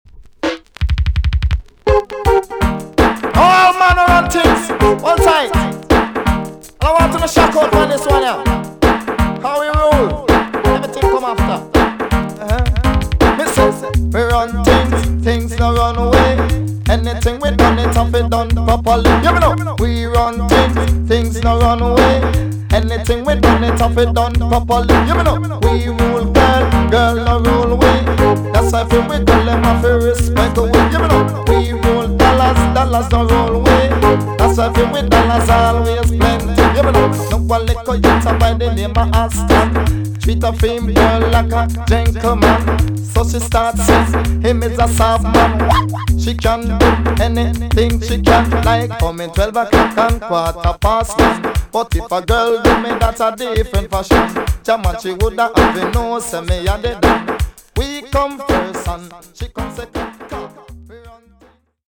TOP >80'S 90'S DANCEHALL
EX- 音はキレイです。
1988 , NICE DJ TUNE!!